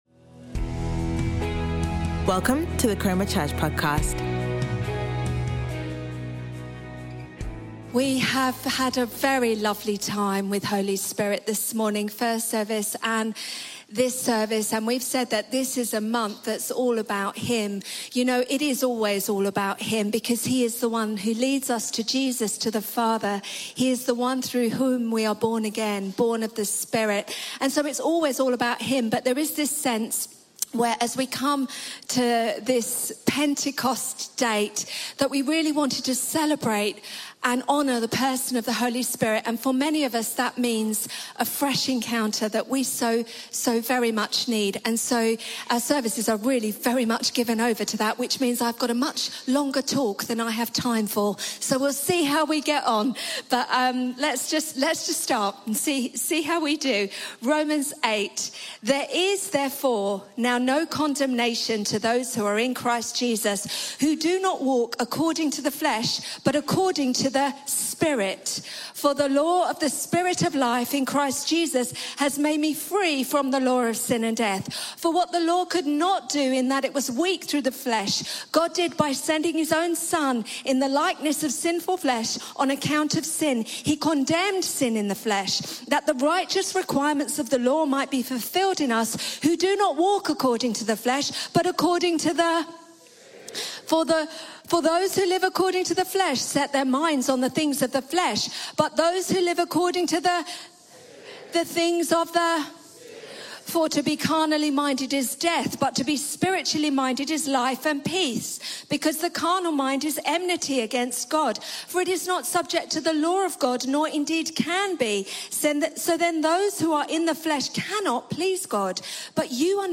Chroma Church Live Stream
Chroma Church - Sunday Sermon